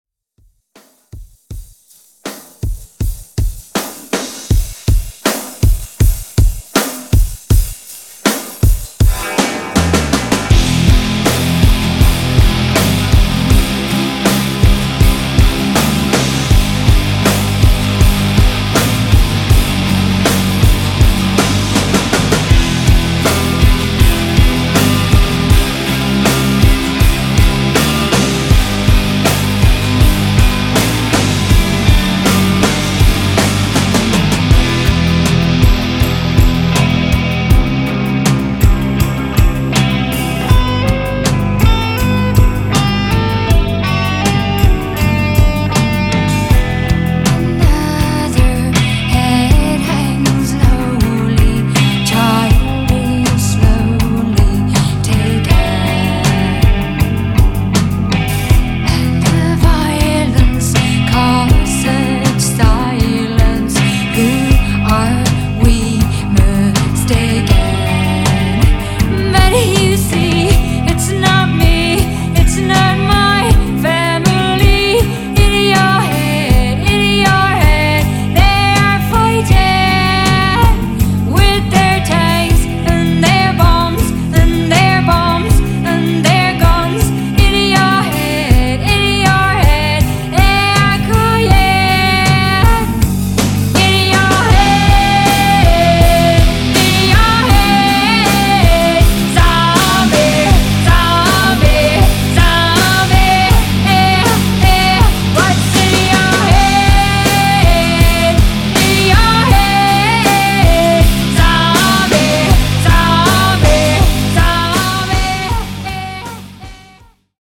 Genres: 80's , RE-DRUM , ROCK
Clean BPM: 80 Time